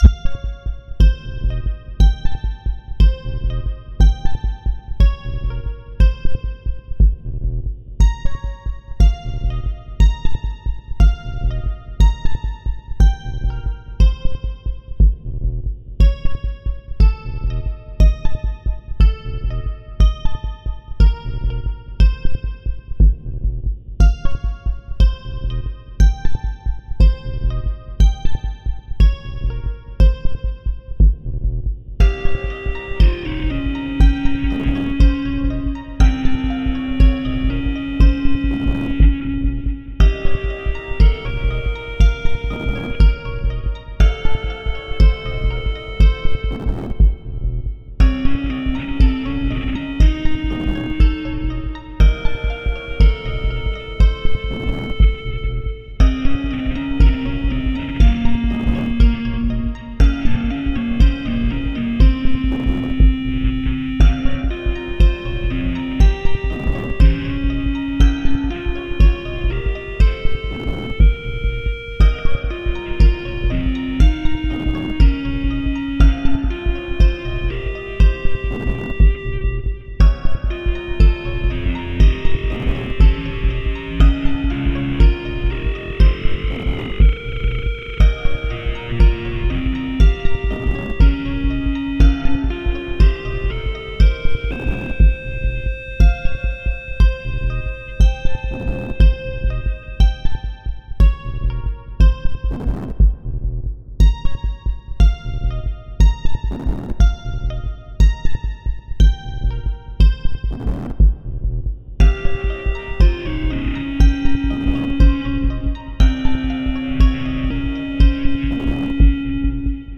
C Dorian b2 so it does have a sense of unease but I think it sounds sad ymmv.
lonely_mournful_tune_0.ogg